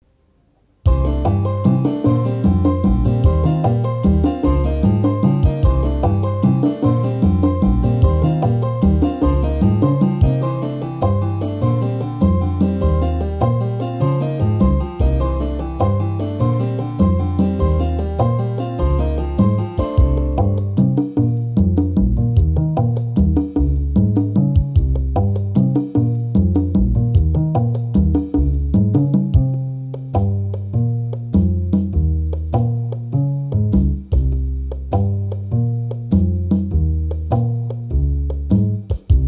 Hier ein Beispiel aus meinem Salsa-Buch wie innerhalb eines 12/8 Taktes die Schwerpunkte von 3 auf 4 und umgekehrt verlagert werden können.